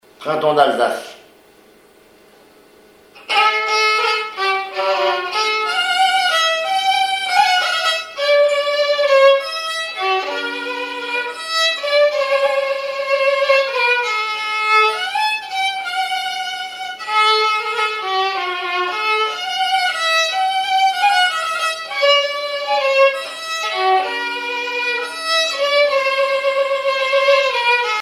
violoneux, violon,
valse musette
Répertoire au violon
Pièce musicale inédite